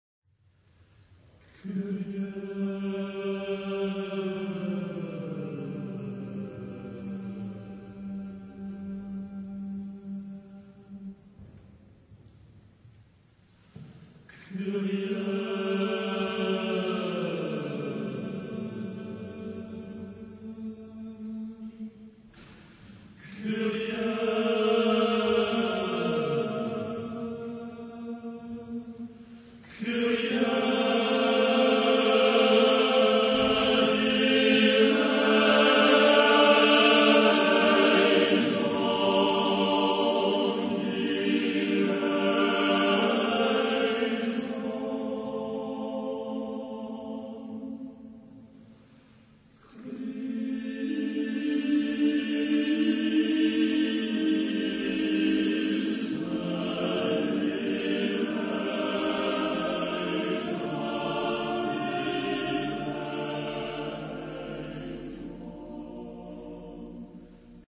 Type de choeur : TTBB  (4 voix égales d'hommes )
Tonalité : libre